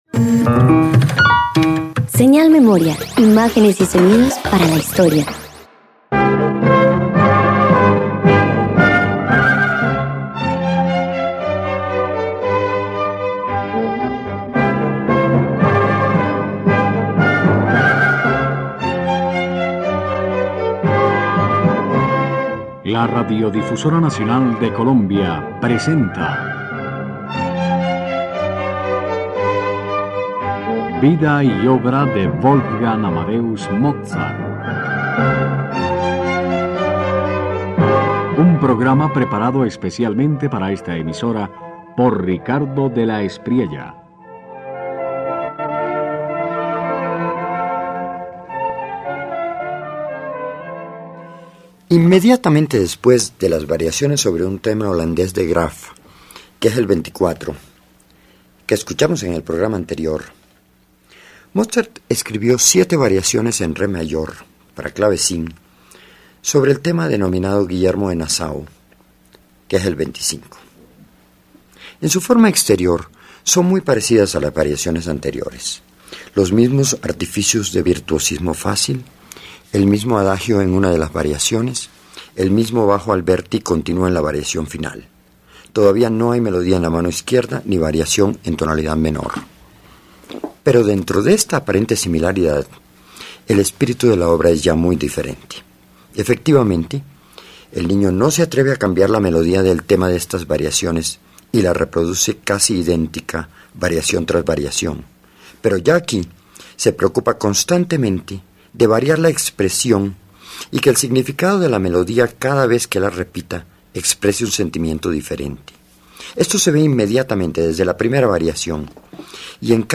011 Variaciones en Re mayor para clavecín 2_0.mp3